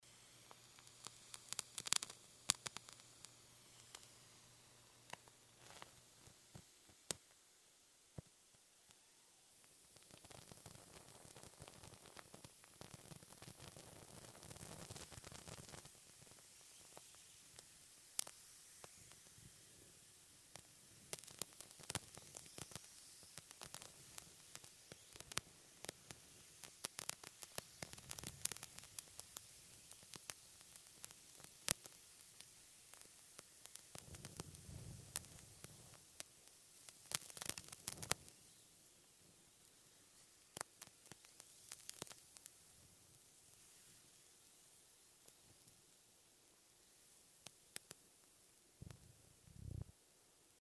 Звуки горения свечей
Потрескивание горящей свечи